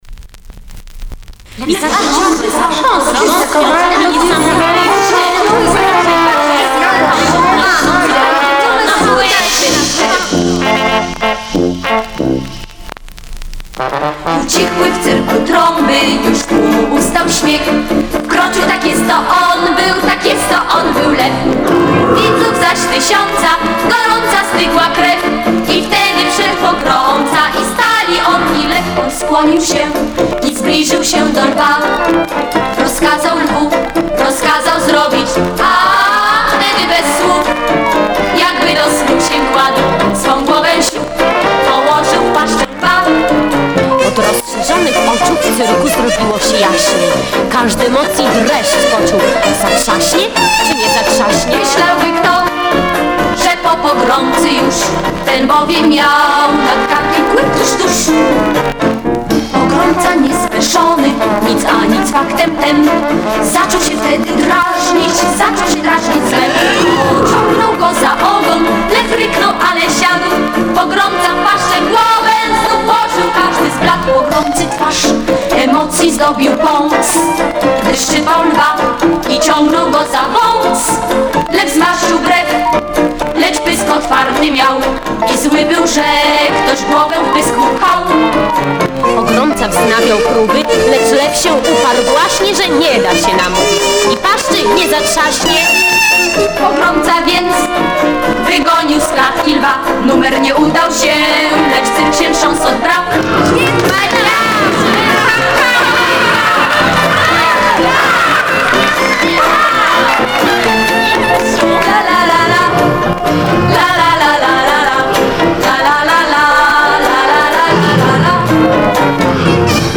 Zenski Zespol Wokalny